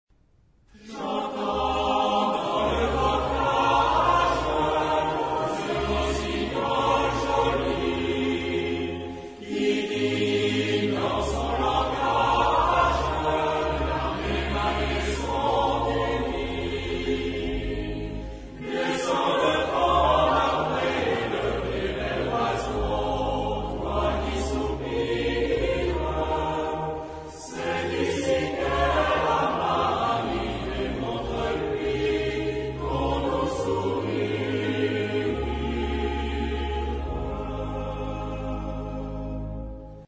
Genre-Style-Forme : Profane ; Populaire
Caractère de la pièce : joyeux
Type de choeur : SATB  (4 voix mixtes )
Solistes : Ténor (1)  (1 soliste(s))
Tonalité : la majeur
Origine : Limousin